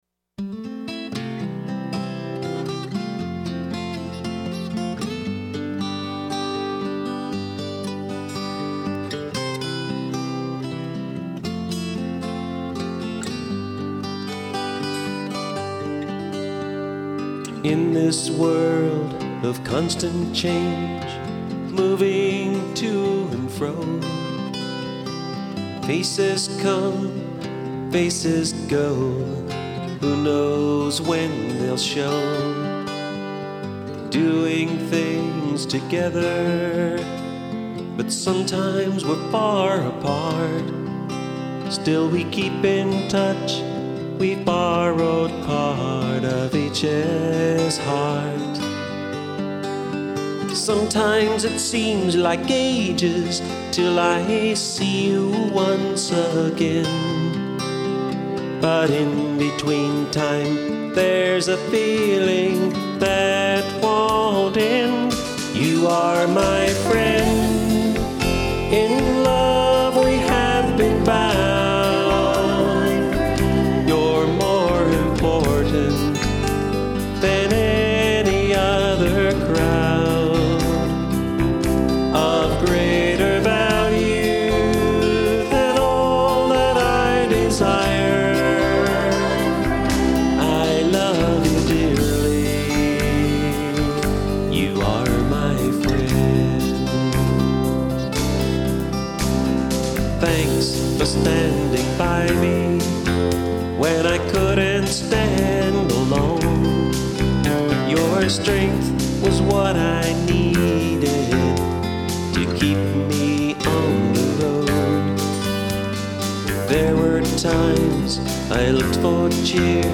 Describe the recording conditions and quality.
This was the first album that we recorded in our own studio.